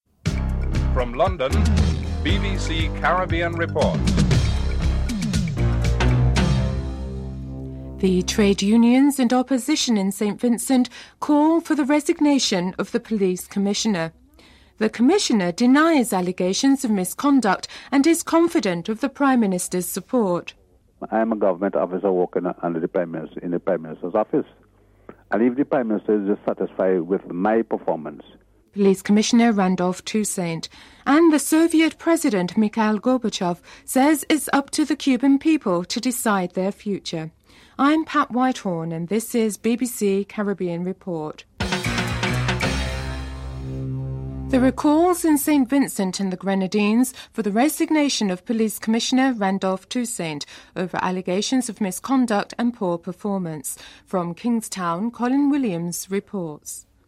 1. Headlines (00:00-00:44)
Comments from the Soviet President, Mikhail Gorbachev, who states that it is up to the Cubans to decide their future (09:21-11:55)